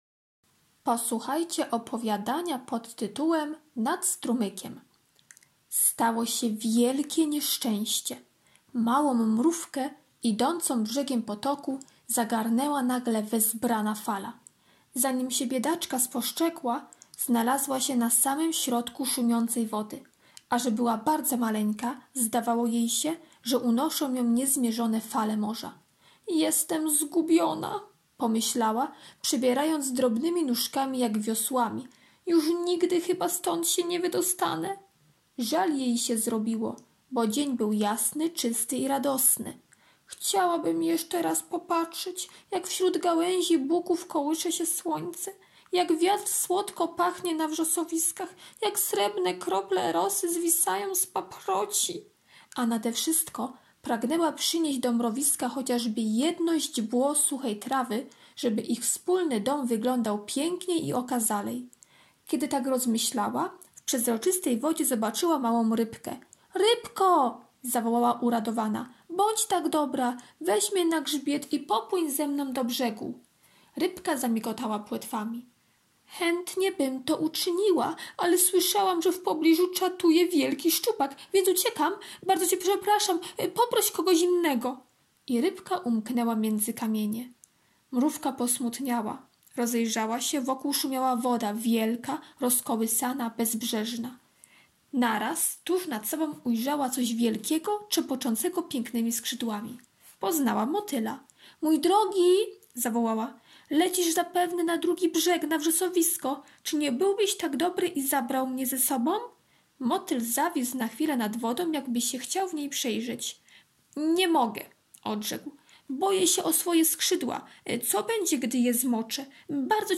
piątek - opowiadanie "Nad strumykiem" [8.76 MB] piątek - prezentacja o mrówce [5.55 MB] piątek - ćw. dla chętnych - pisanie litery Z, z [251.80 kB] piątek - ćw. dla chętnych - kolorowanka sylabowa [630.51 kB] piątek - ćw. dla chętnych "Znajdź różnicę" [322.03 kB]